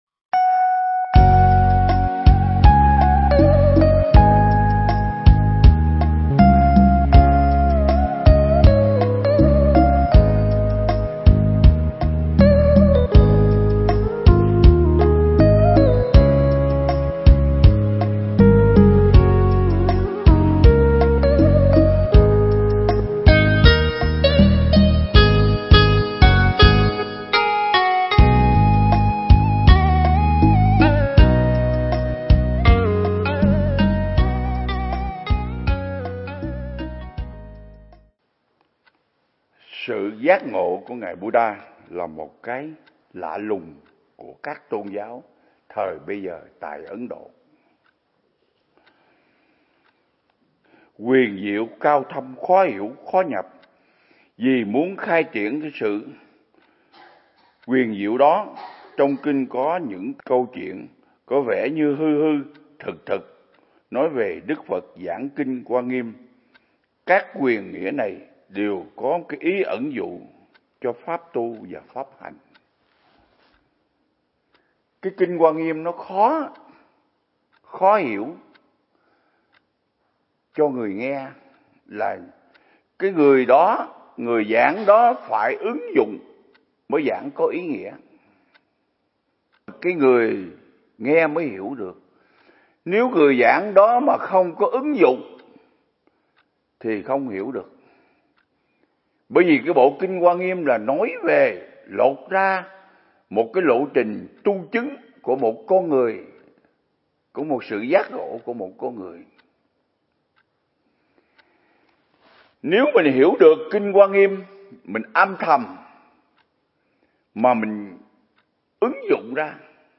Mp3 Pháp Thoại Ứng Dụng Triết Lý Hoa Nghiêm Phần 10
giảng tại Viện Nghiên Cứu Và Ứng Dụng Buddha Yoga Việt Nam